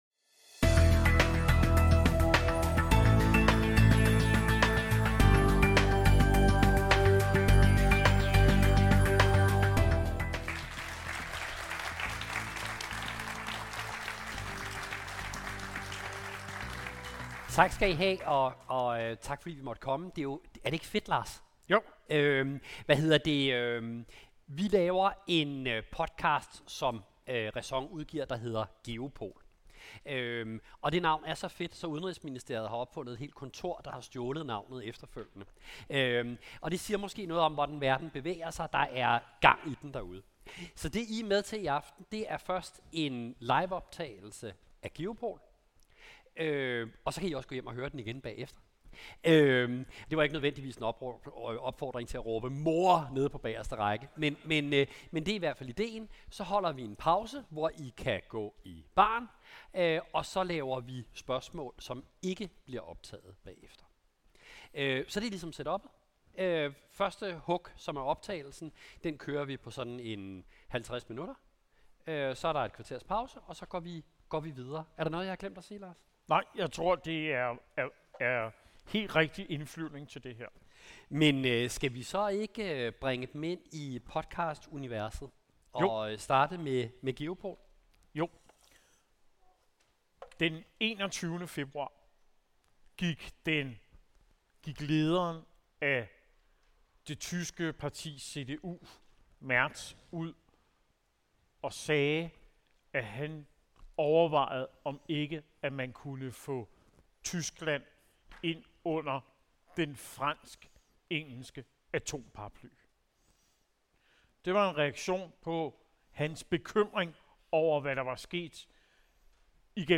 i denne særudgave live fra Kulturstationen i Skørping